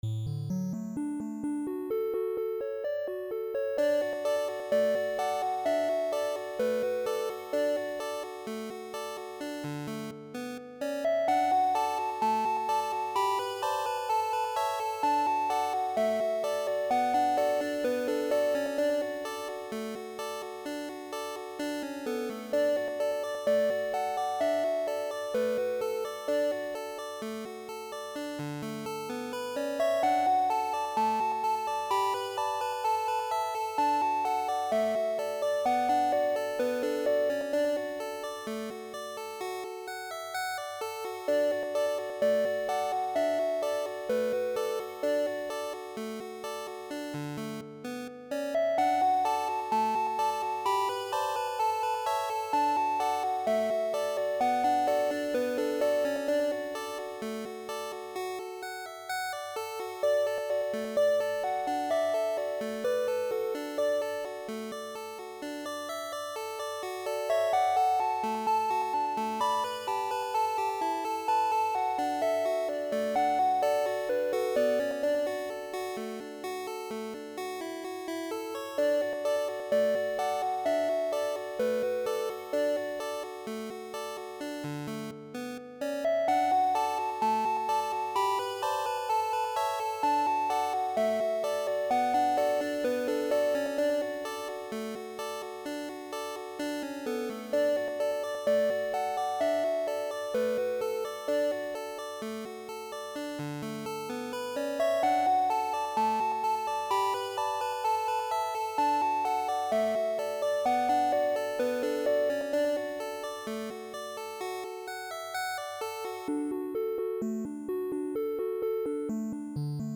I found an old 19th century folk melody and thought I'd make a simple 8-bit like arrangement of it.